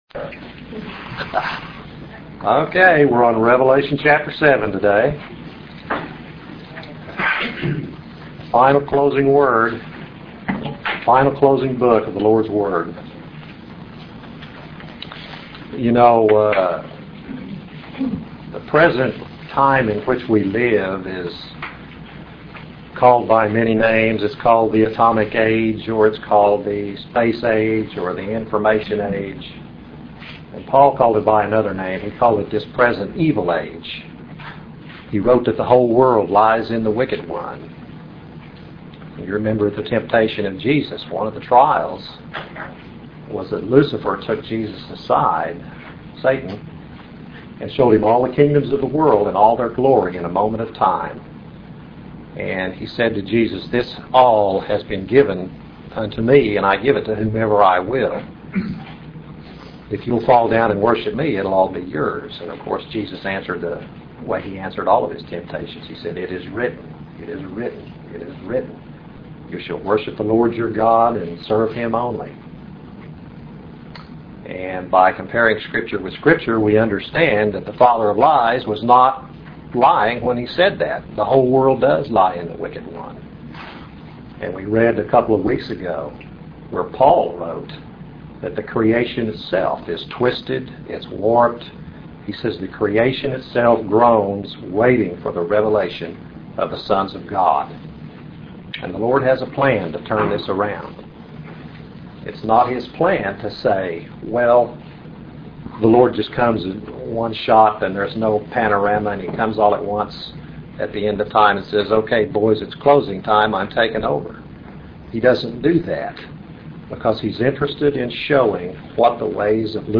Book of Revelation - A Verse by Verse Audio Study - Chapter 7 - 144,000 Sealed